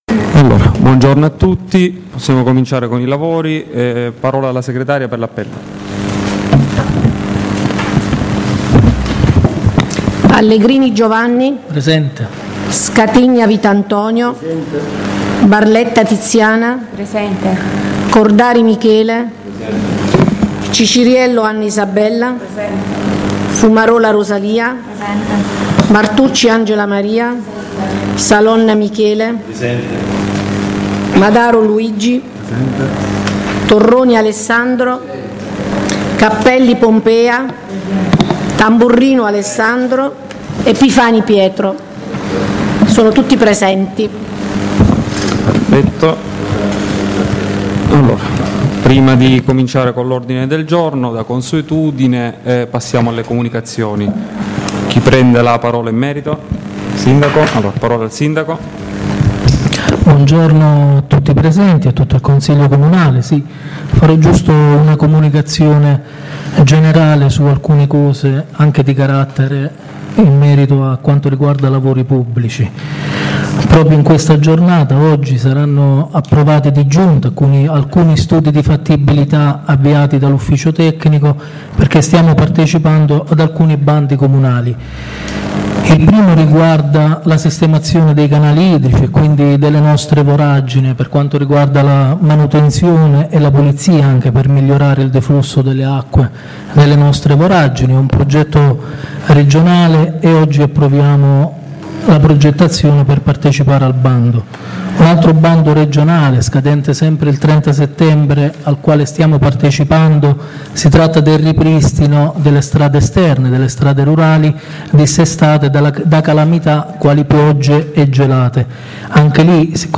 La registrazione audio del Consiglio Comunale di San Michele Salentino del 27/09/2019: